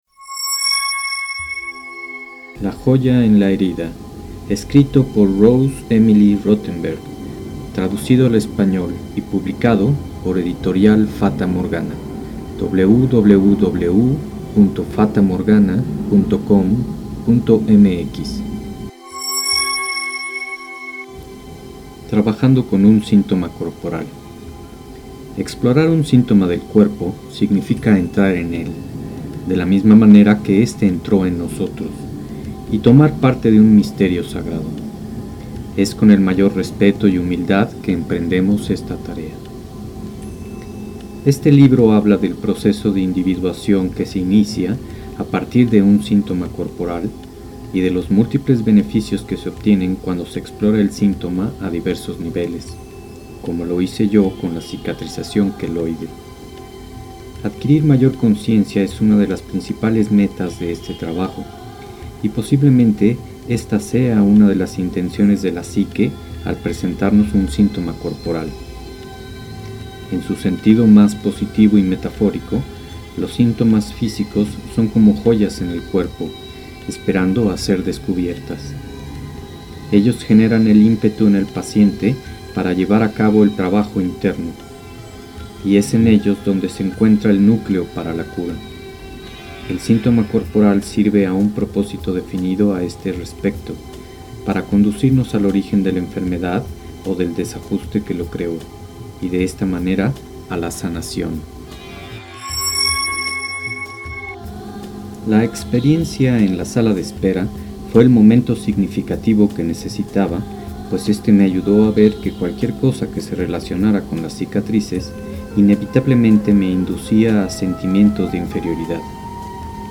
Lecturas de: La joya en la herida